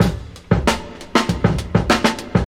Drum loops
Original creative-commons licensed sounds for DJ's and music producers, recorded with high quality studio microphones.
97-bpm-breakbeat-f-sharp-key-UaN.wav